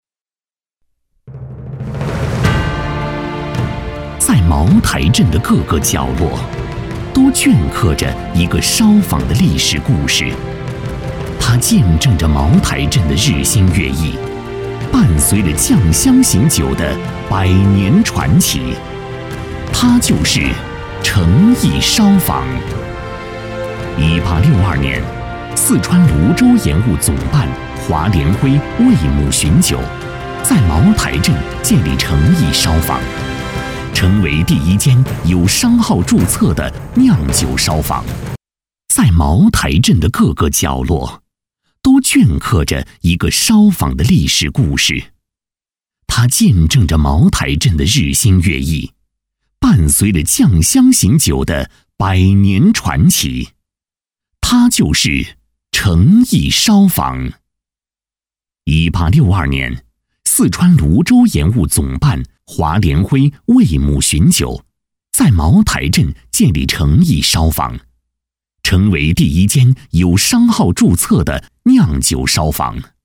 配音风格： 磁性，年轻
【专题】茅台镇